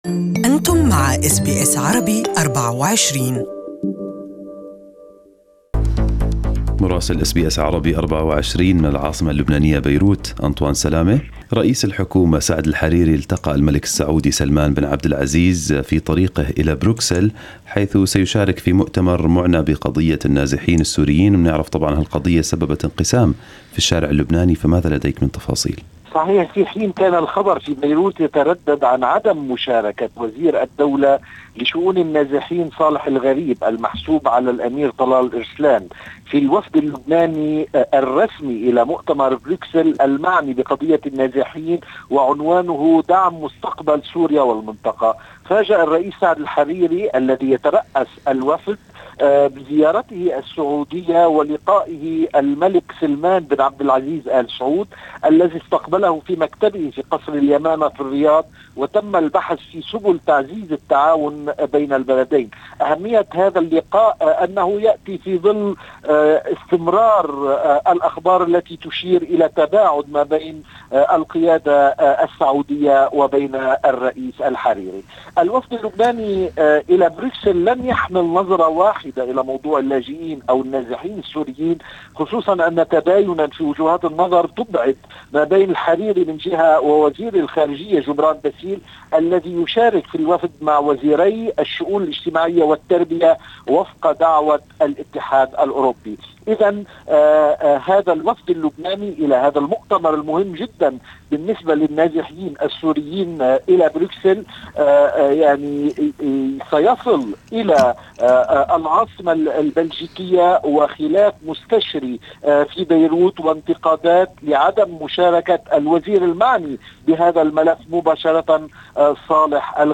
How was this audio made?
Listen to the full report from Beirut in Arabic above